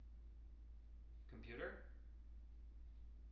wake-word
tng-computer-336.wav